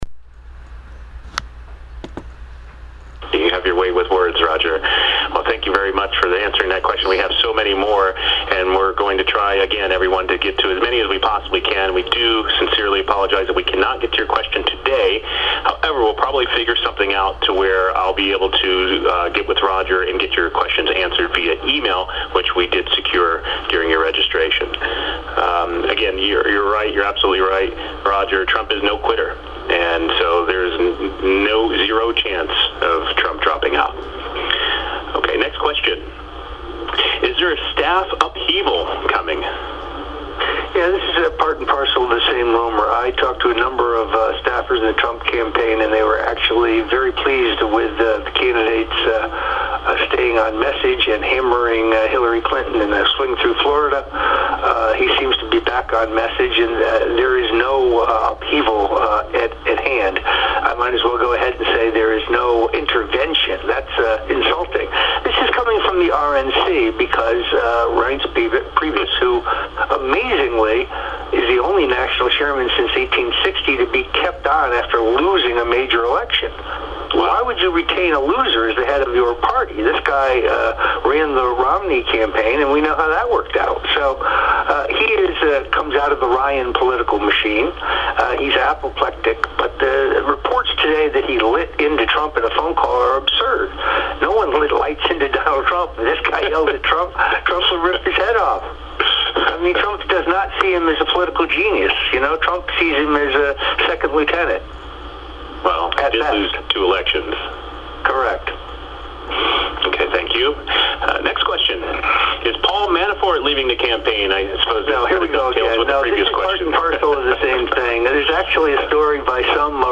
(Oct. 29, 2018) — On August 4, 2016, longtime political strategist and informal Trump advisor Roger Stone spoke on the first of what would be five conference calls about his views on Donald Trump’s presidential campaign and prospects of winning over Democrat Hillary Clinton.
The recording below is the third segment in our series presenting the initial call.
The public was invited beforehand to register through one of Stone’s websites and at the same time submit any questions to be put to Stone if time permitted.